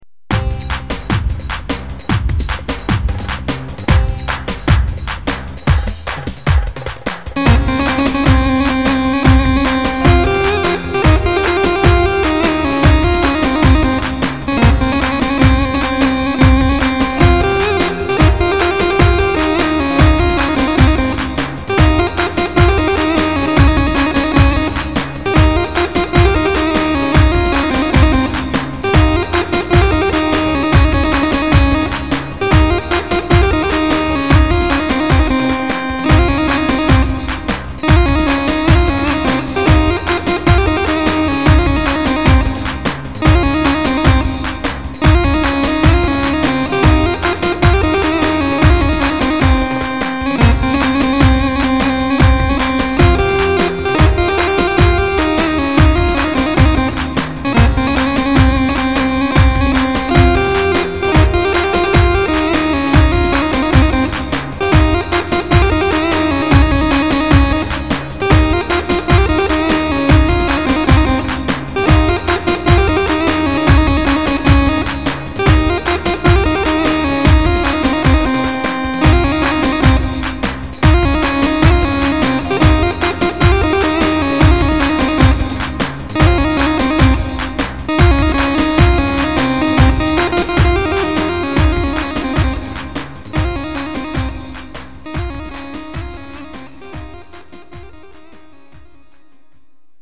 Korg Pa500 Tr -Daykolu Kız 2012 Roman ....